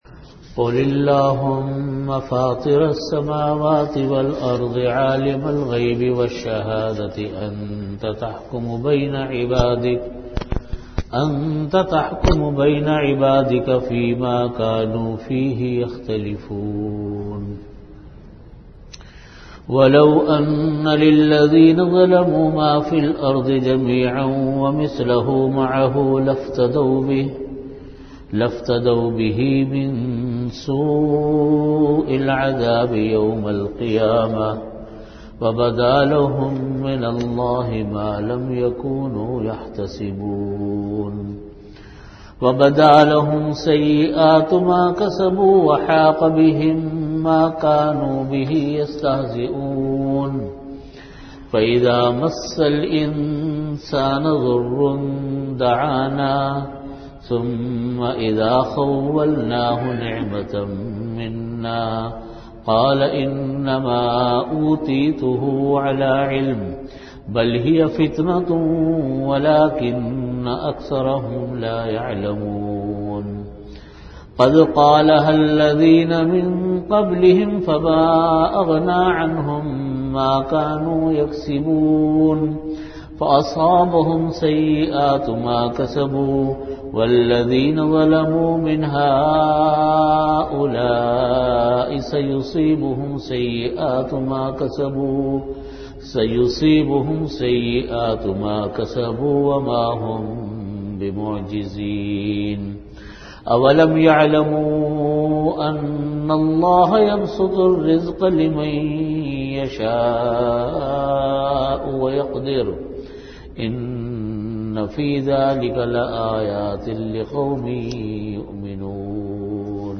Tafseer
Time: After Asar Prayer Venue: Jamia Masjid Bait-ul-Mukkaram, Karachi